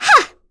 Yuria-Vox_Attack1.wav